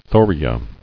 [tho·ri·a]